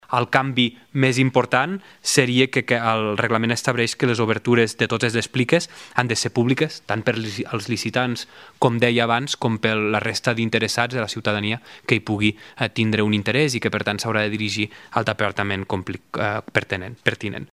Així ho ha explicat el ministre portaveu, Guillem Casal, que ha detallat que el nou reglament estableix que l’obertura de totes les pliques han de ser públiques.